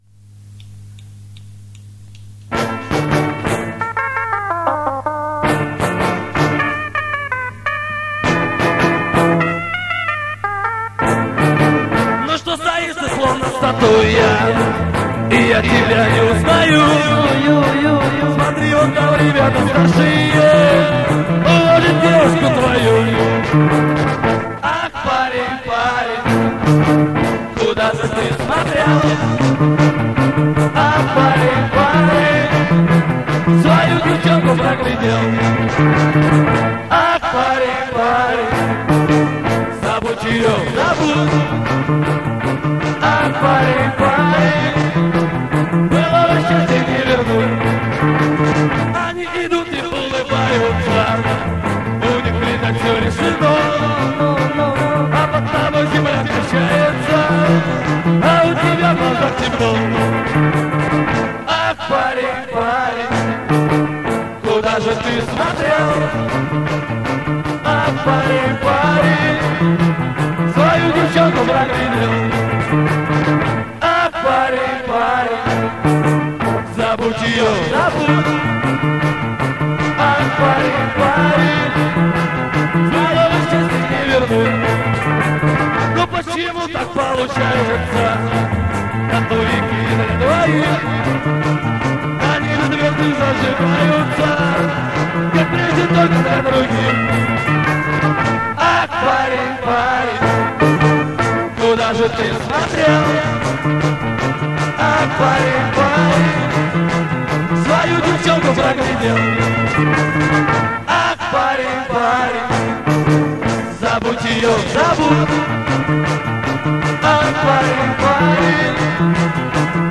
Предновогодняя просьба к завалинским меломанам: здесь на завалинке нашлась запись видимо из начала семидесятых, такие звучали на танцплощадках в исполнении местных "битлов" и вроде бы текст несовершенный и мелодия из блатных, но есть какое то очарование тех лет в этой записи и ведь неплохо исполнено. Помогите пожалуйста выровнять звуковые провалы в этом раритете.